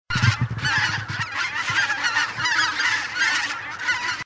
Trekroepjes Ganzen